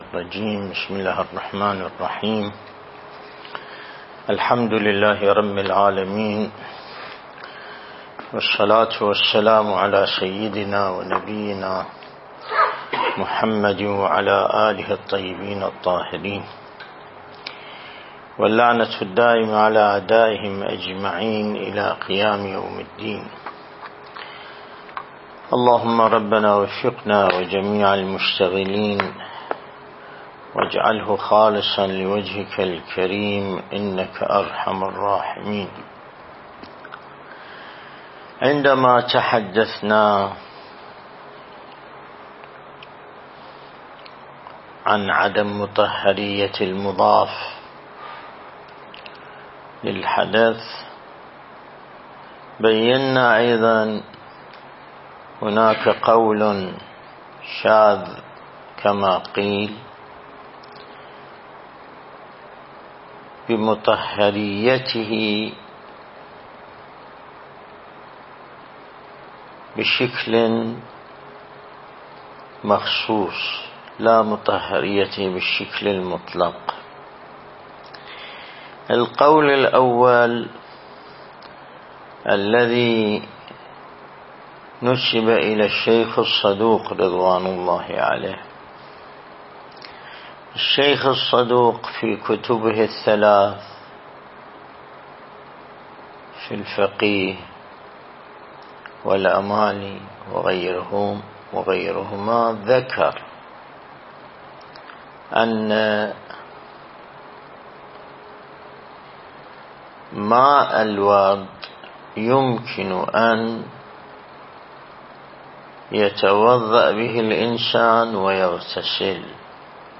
الدرس الاستدلالي